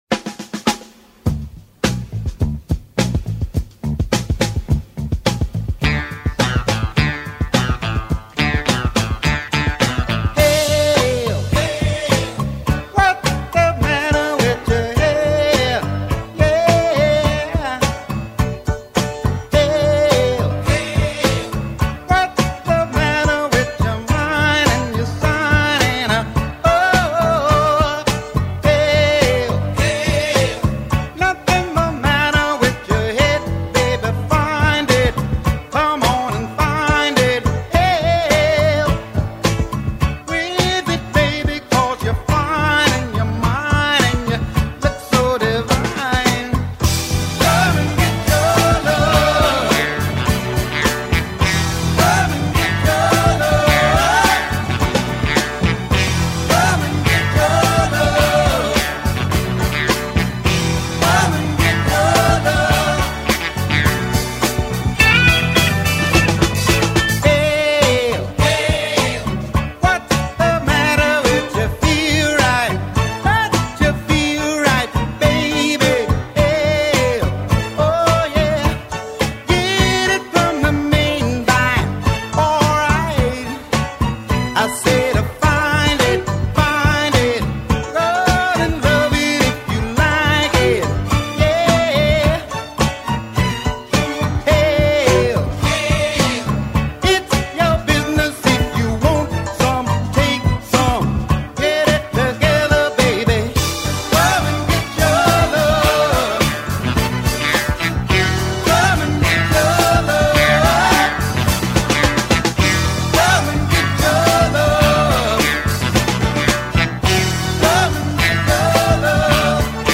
vocals and bass guitar
vocals and guitar
has a disco beat